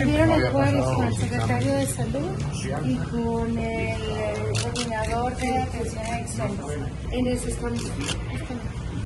Durante su llegada, la gobernadora Maru Campos Galván fue entrevistada por medios de comunicación.